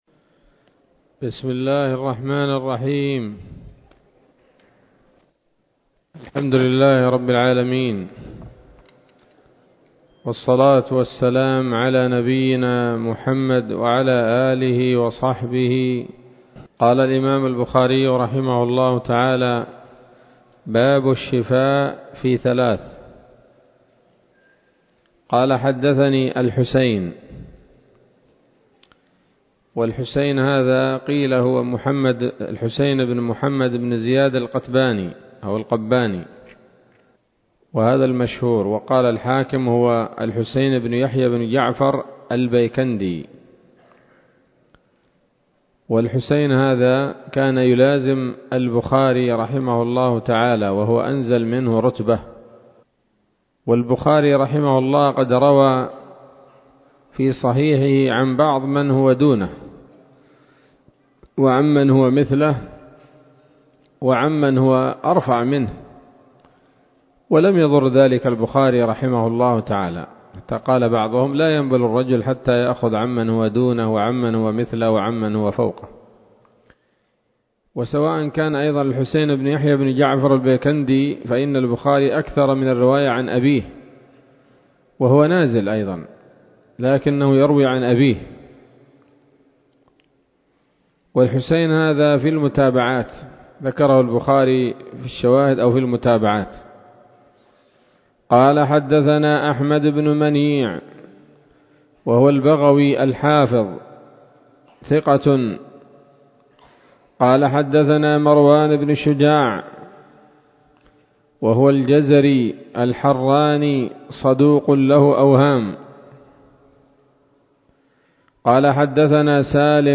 الدرس الثالث من كتاب الطب من صحيح الإمام البخاري